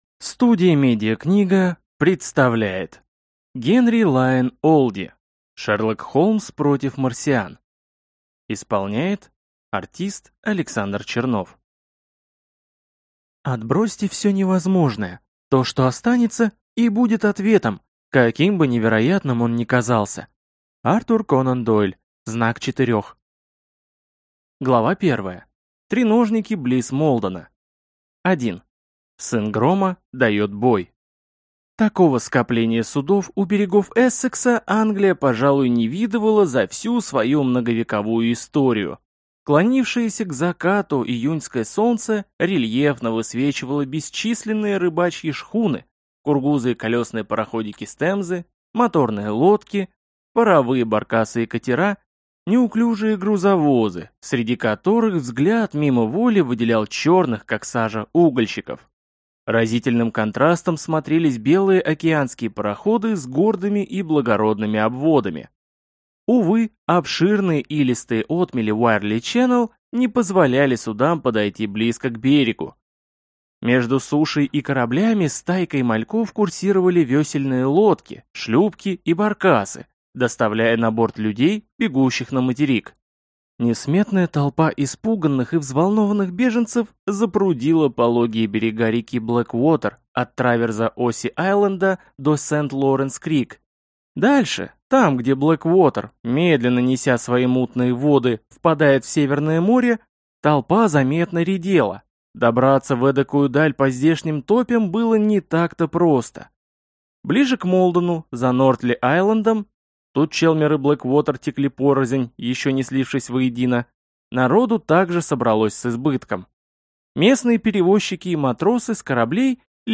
Аудиокнига Шерлок Холмс против марсиан | Библиотека аудиокниг